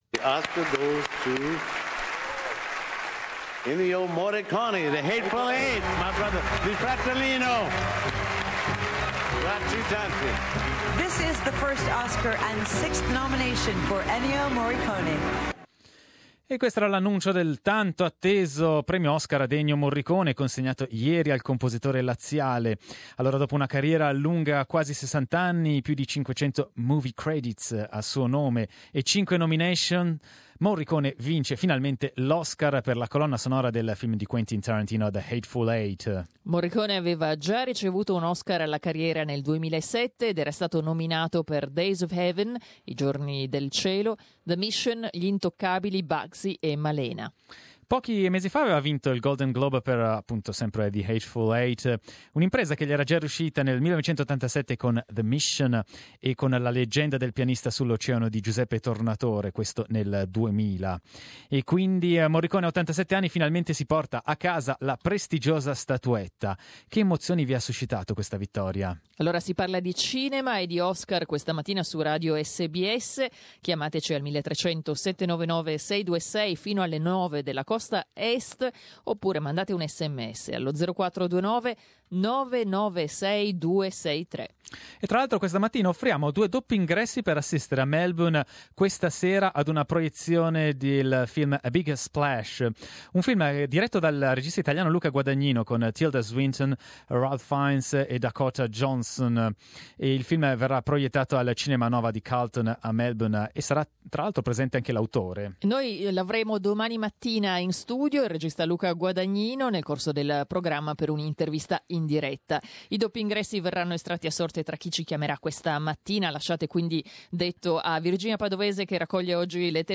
Oggi su Radio SBS abbiamo parlato di cinema con i nostri ascoltatori. Un commento all'assegnazione degli Oscar, a partire da quello ad Ennio Morricone per la colonna sonora del film di Quentin Tarantino "The Hateful Eight".